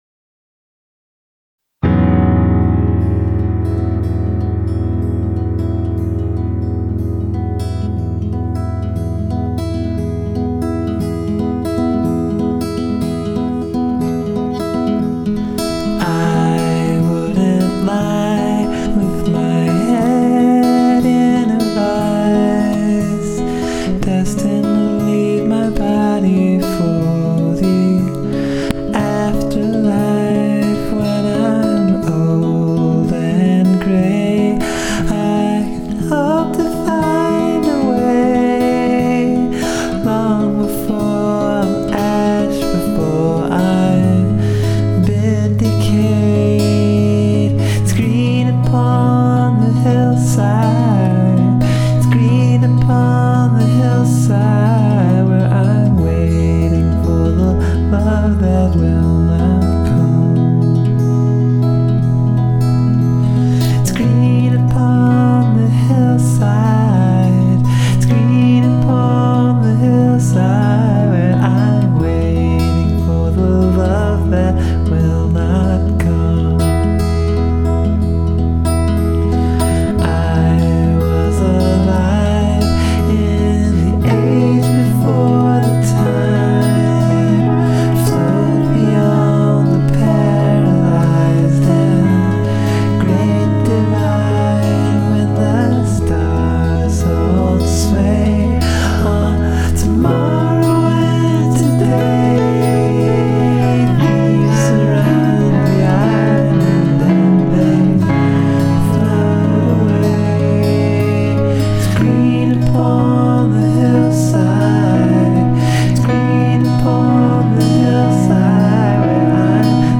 The biggest change I've made to this is a reorganization of the cello and piano parts as well as the addition of a bass organ (but I'm thinking about reworking that with a bass guitar).